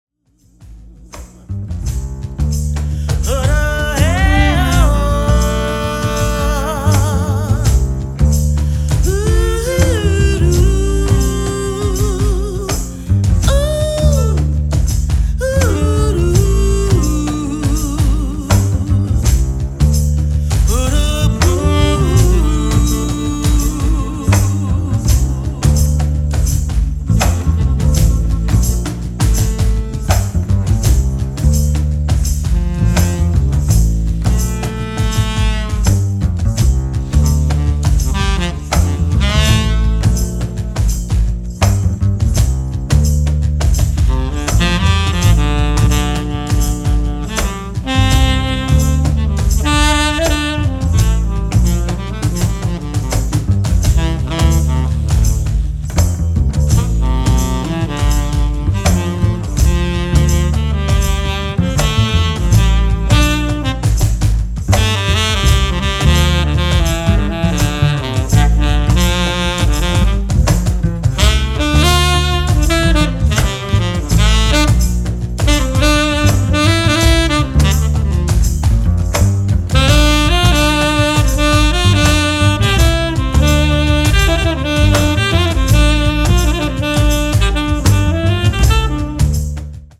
Tenor Sax and Piano